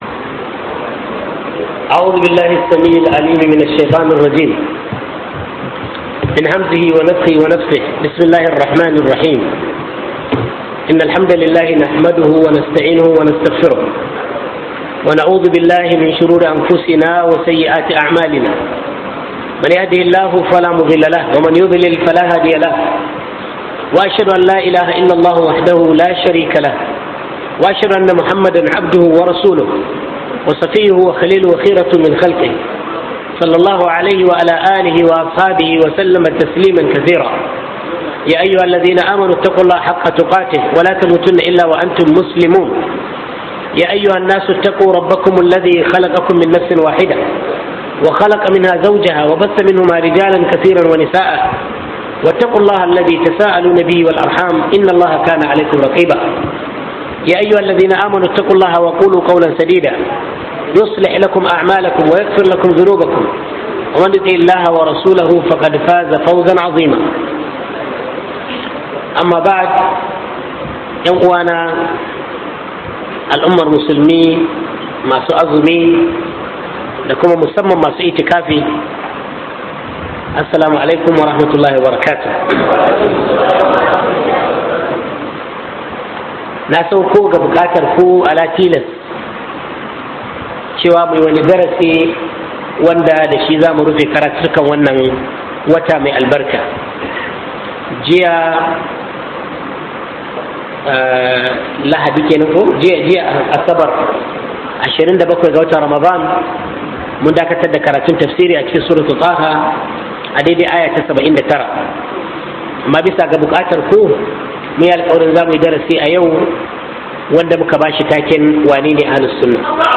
WANENE AHLUL SUNNAH - MUHADARA